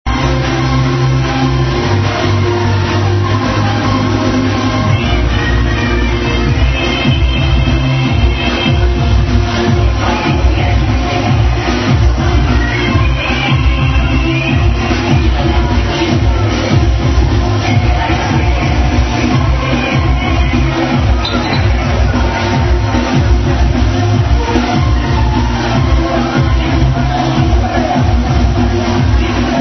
Commercial-ish bouncy club tune
maybe some happy hardcore track ?